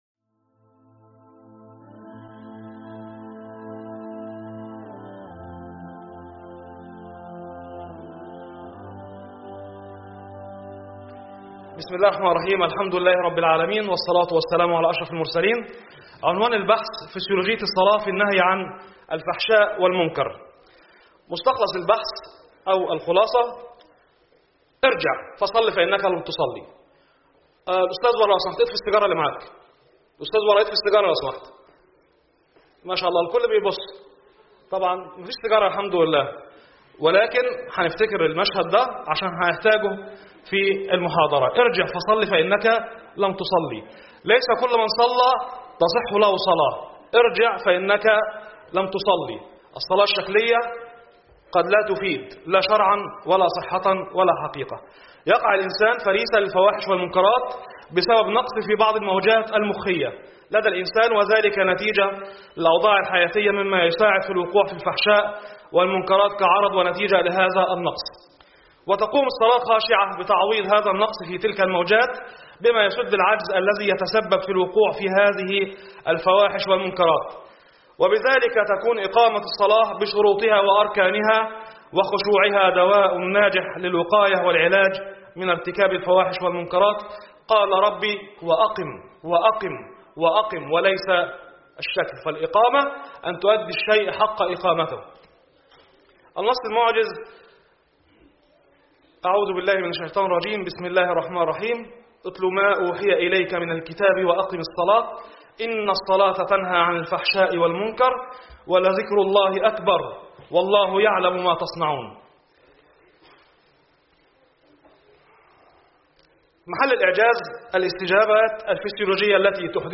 بالمؤتمر السنوى التاسع للإعجاز العلمى